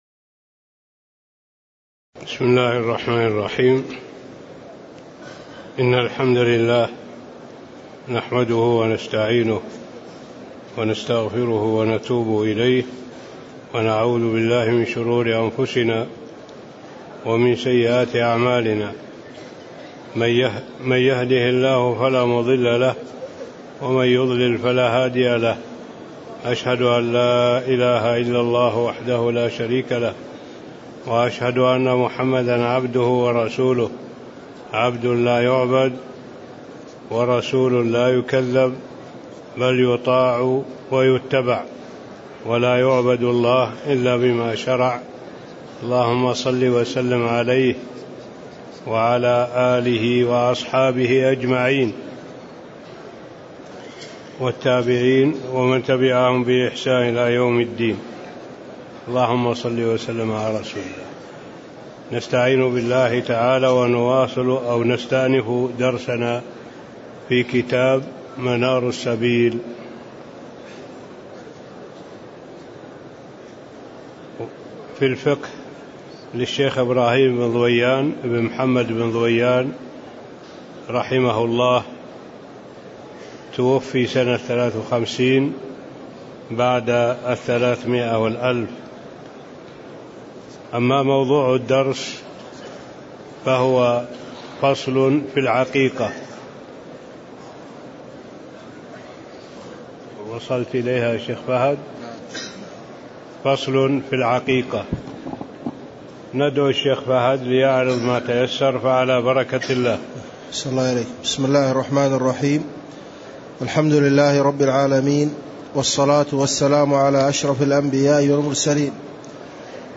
تاريخ النشر ٢٧ ذو الحجة ١٤٣٦ هـ المكان: المسجد النبوي الشيخ